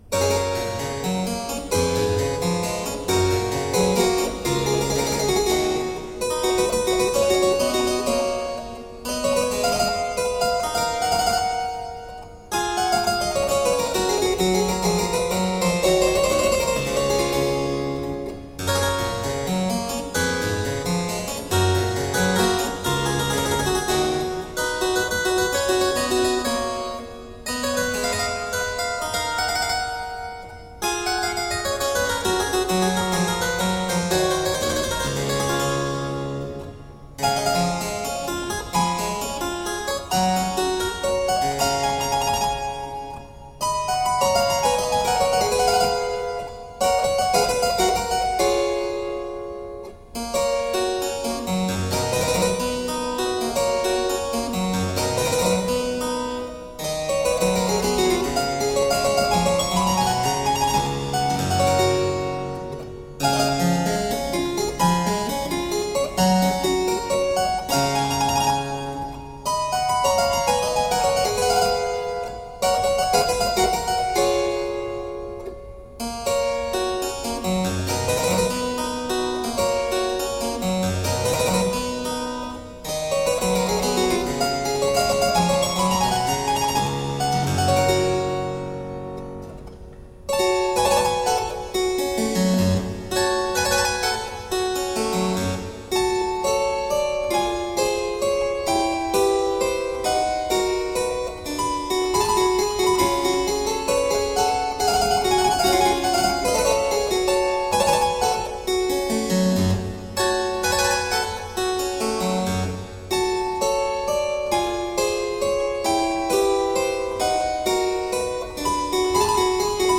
Crisp, dynamic harpsichord.